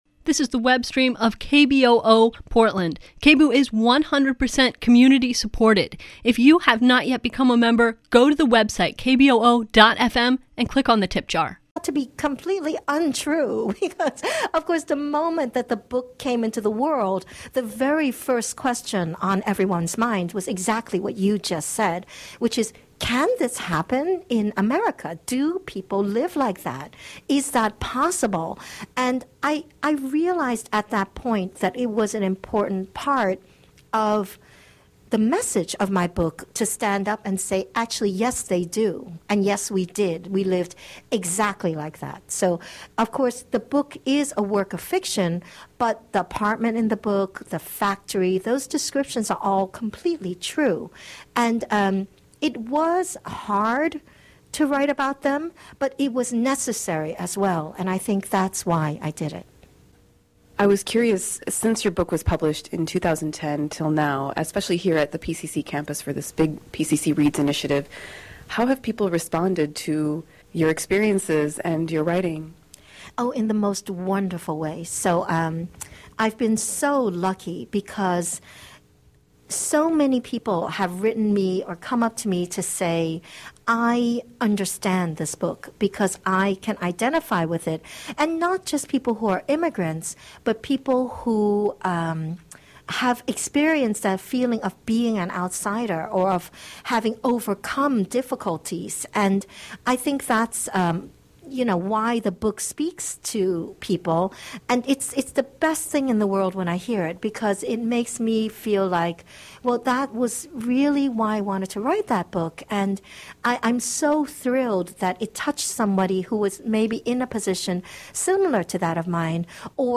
Every third Thursday from 11:00 AM to 12:00 PM A weekly show featuring interviews with locally and nationally known authors of both fiction and non-fiction.